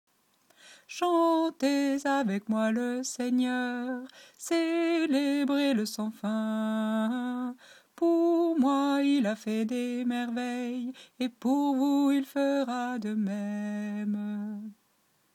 TENOR